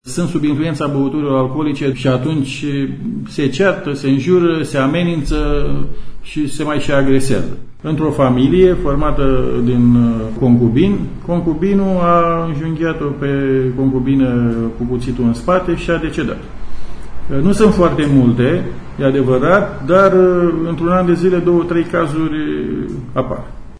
Inspectorul şef al Inspectoratului Judeţean de Poliţie, Viorel Ştefu, precizează că anual, pe fondul consumului de alcool, se înregistrează, în medie, câte 3 cazuri de omor: